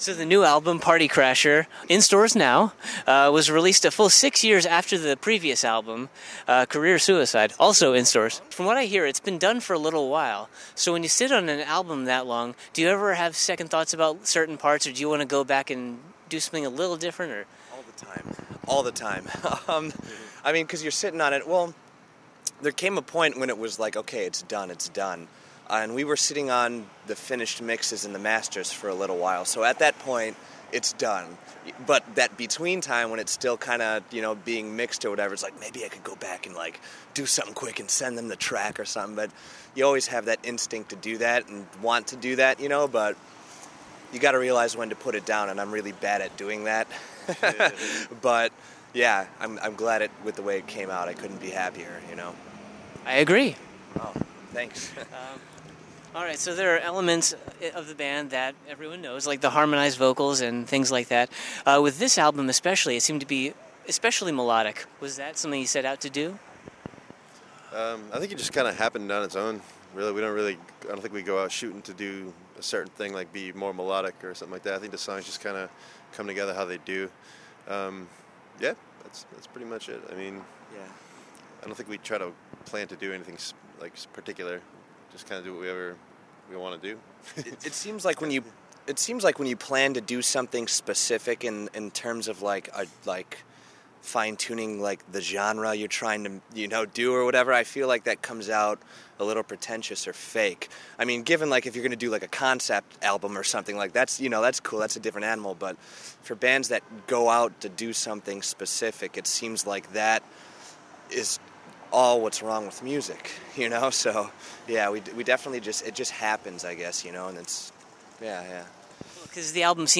Exclusive: A Wilhelm Scream Interview 2.0
interview-a-wilhelm-scream-2013.mp3